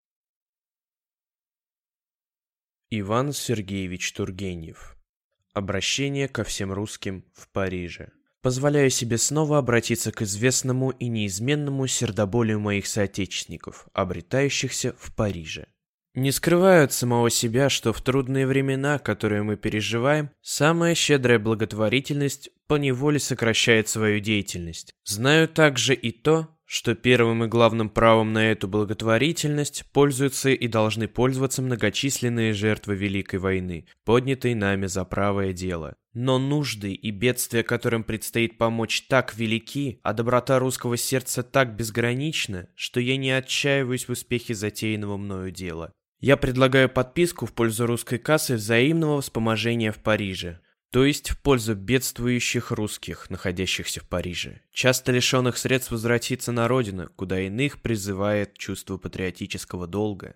Аудиокнига Обращение ко всем русским в Париже | Библиотека аудиокниг